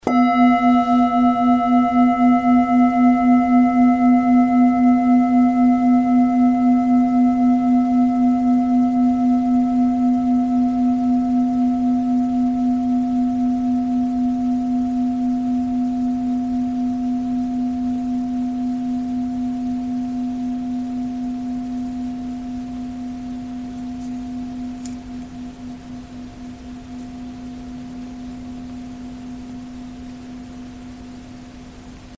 Struck or rubbed, the bowls produce a long-lasting, overtone-rich, and fine sound.
Sound sample Arhat singing bowl 700g:
Arhat-Klangschale-700g-Hoerprobe.mp3